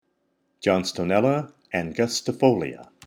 Pronunciation/Pronunciación: John-sto-nél-la an-gus-ti-fò-li-a Etymology/Etimología: "narrow-leaved" Synonyms/Sinónimos: Homotypic: Eritrichium angustifolium Torr.